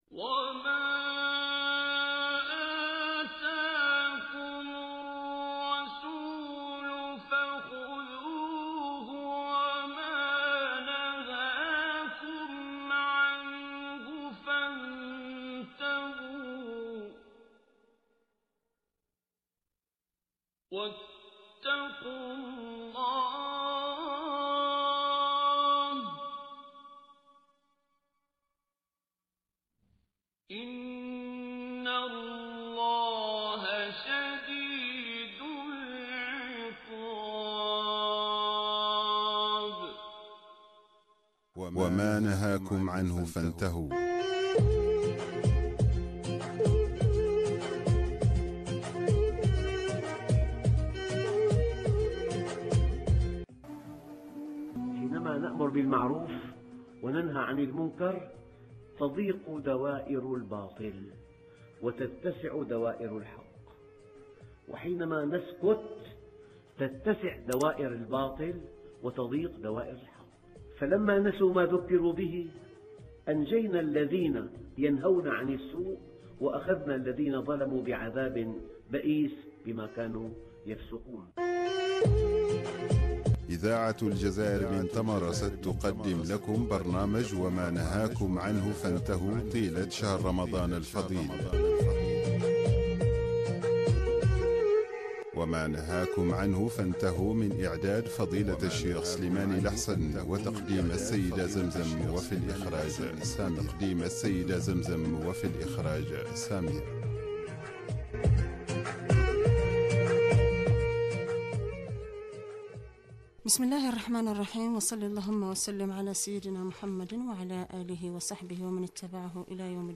برامج إذاعية